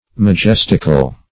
Search Result for " majestical" : The Collaborative International Dictionary of English v.0.48: Majestical \Ma*jes"tic*al\, a. Majestic.